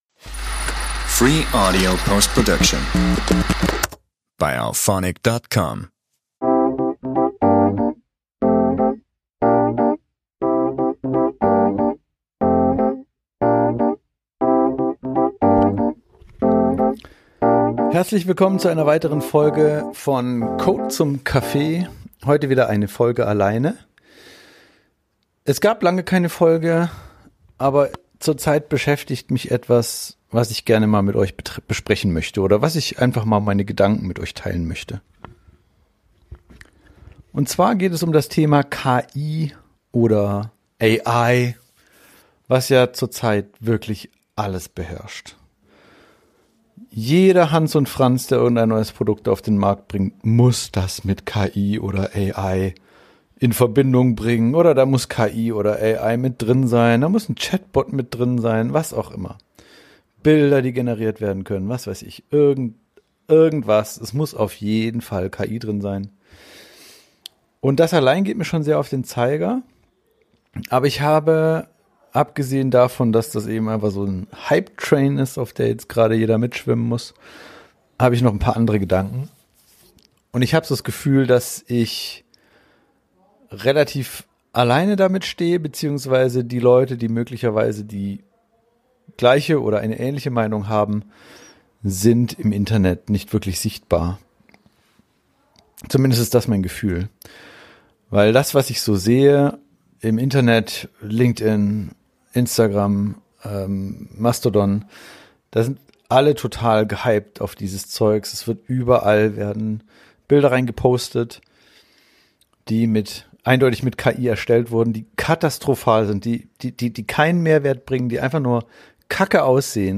Ein alter weisser Mann schimpft über die Welt
Seit einigen Monaten sprechen wir jeden Samstag miteinander und erzählen uns, was wir so in der Welt der iOS-Entwicklung erlebt und was wir neues gelernt haben.